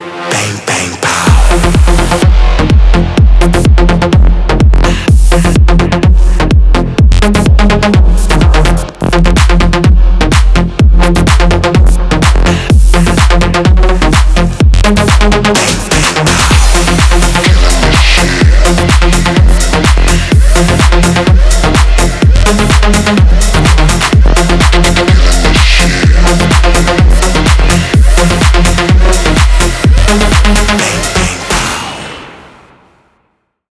Танцевальные рингтоны , Рингтоны техно
electronic , EDM , Club House